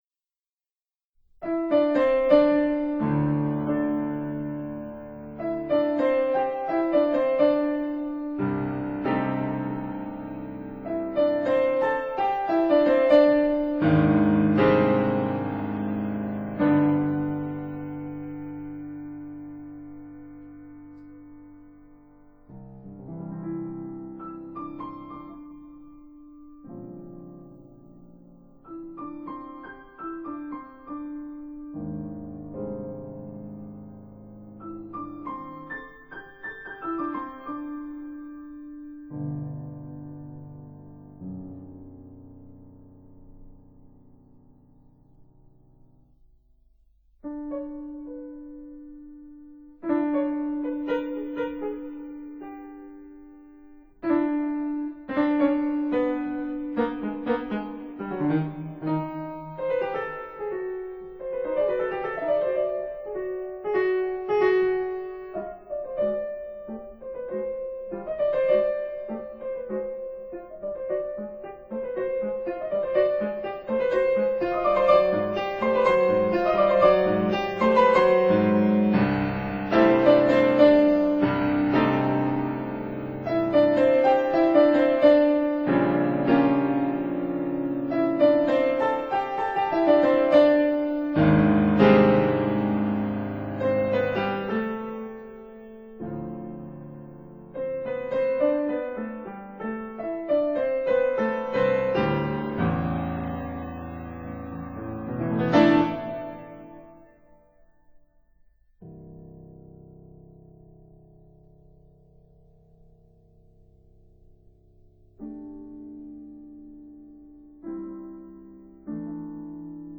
•(01) Sonata for cello and piano, Op. 12